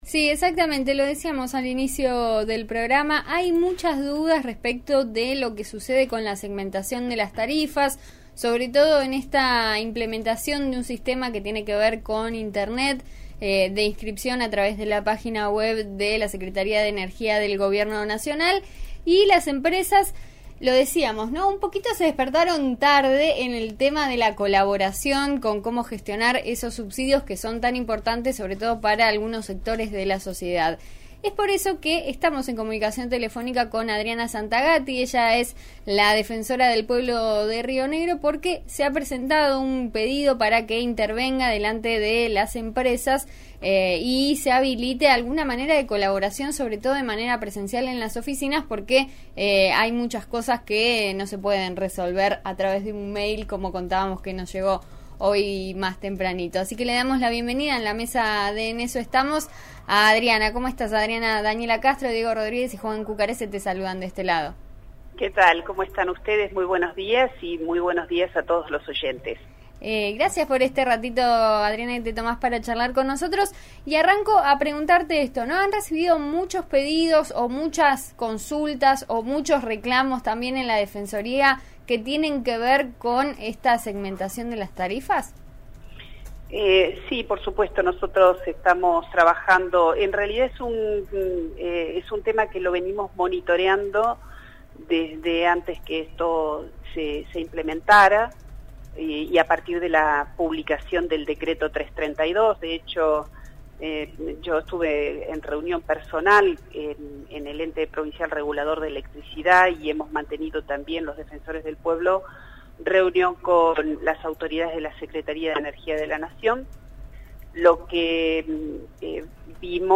En eso estamos de RN Radio entrevistó a Adriana Santagati, defensora del pueblo de Río Negro, sobre la la segmentación de tarifas y su aplicación en la provincia.